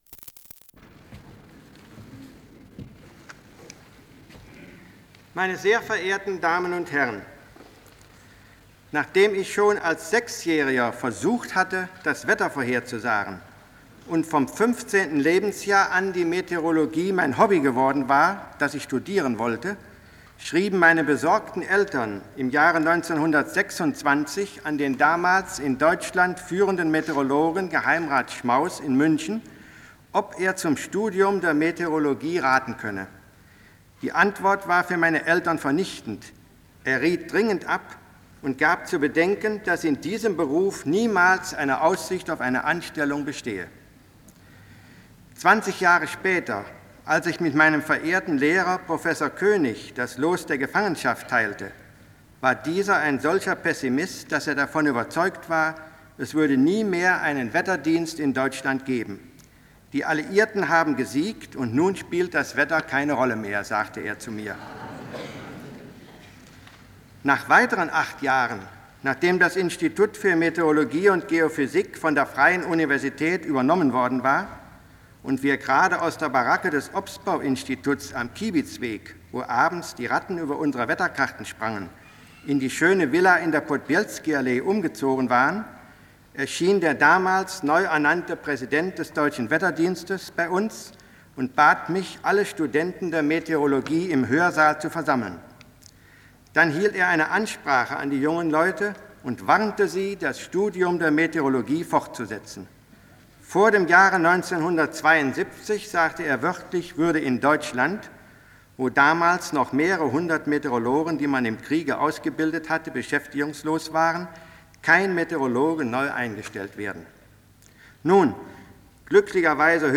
Die Rolle der Meteorologen in der Welt der Zukunft - Vortrag von Prof. Dr. Richard Theodor Anton Scherhag (1966)